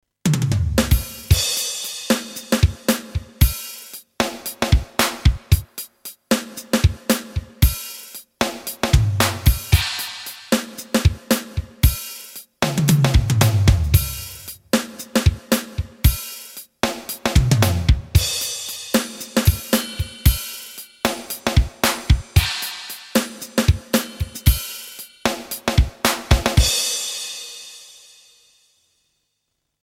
なお、リンク先はSC-8850におけるそれぞれのドラムセットの音色を録音したものです。
*1 リンク先はSC-8850による該当音色でのサンプル演奏です。